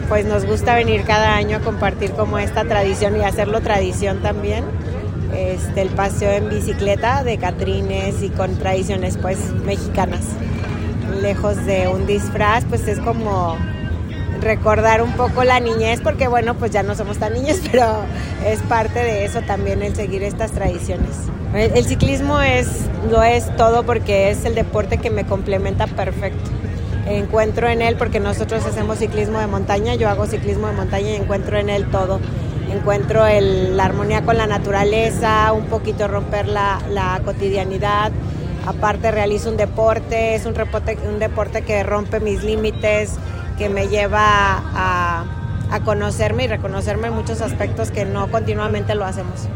participante